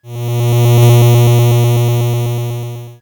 Hum34.wav